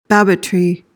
PRONUNCIATION:
(BAB-uh-tree)